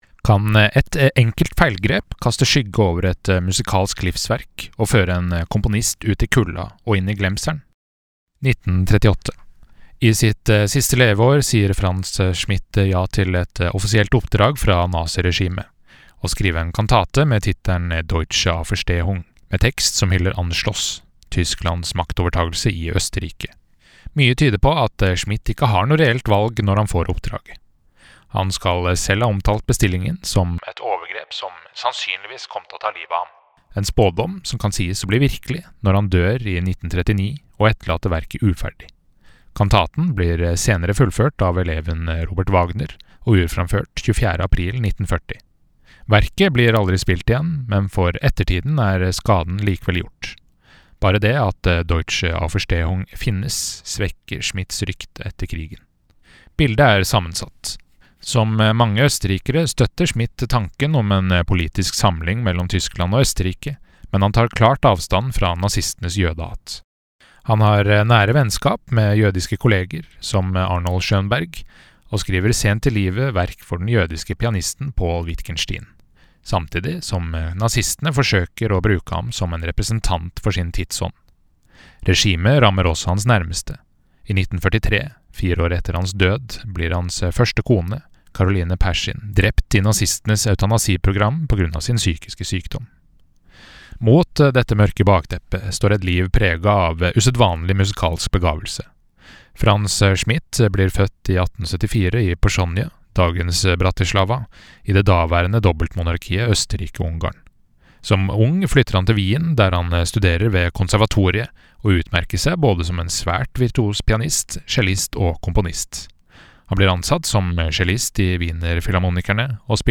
Lytt til verkomtalen VERKOMTALE: Franz Schmidts Symfoni nr. 2 Kan et enkelt feilgrep kaste skygge over et musikalsk livsverk og føre en komponist ut i kulden og inn i glemselen?